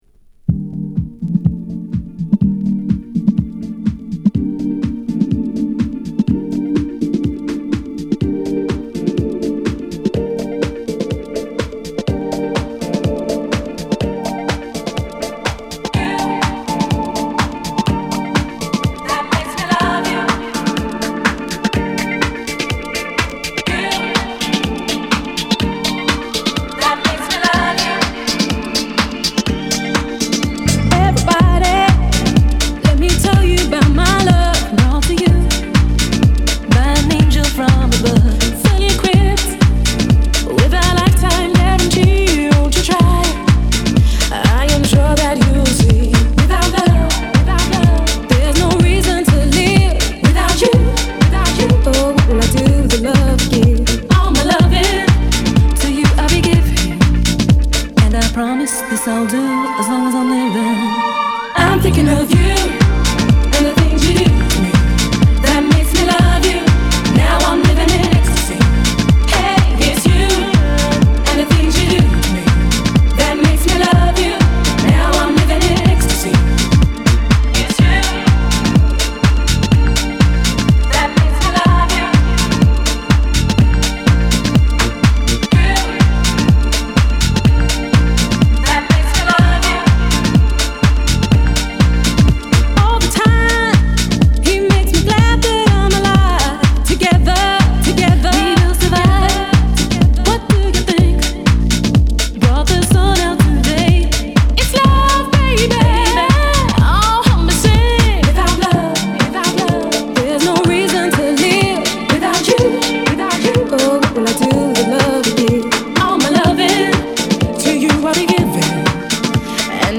グラウンド・ビート・ヴァージョン